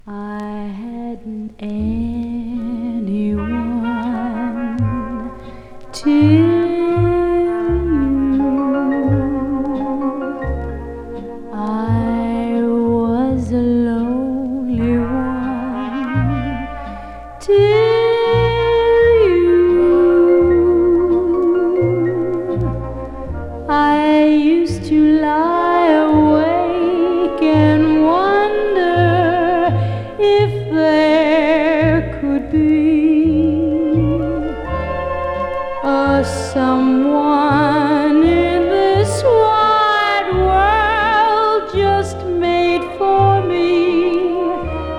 スタンダード楽曲を、伸びやかな歌声がとても良いです。個性としての表現の良さをじっくり感じられるバラード曲も素敵です。
Pop, Vocal, Jazz　USA　12inchレコード　33rpm　Mono